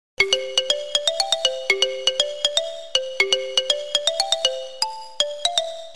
Zombie Sound